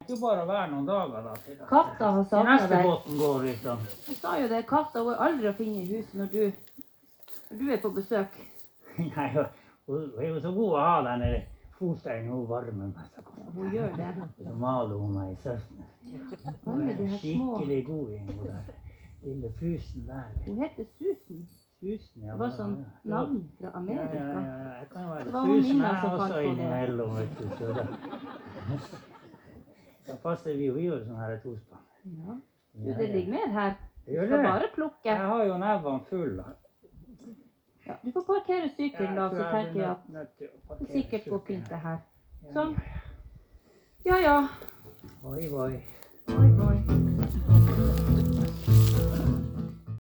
Allerede i første scene ble det knust en flaske på scenen.
Hør den spontane samtalen som oppsto mellom Eeva og Raha-Nilsi mens glasskårene ble plukket:
Plukke-glass.mp3